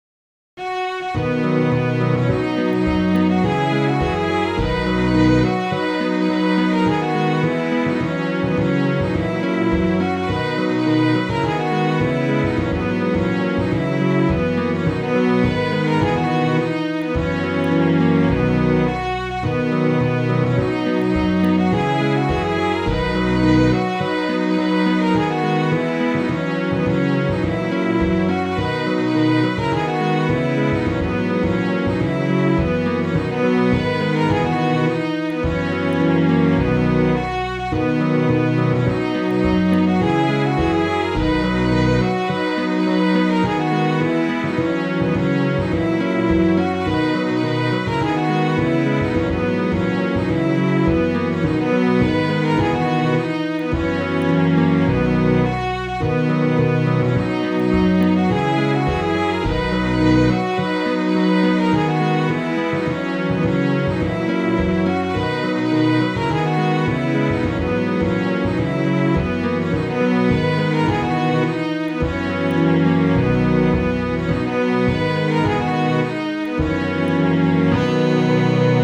roses.mid.ogg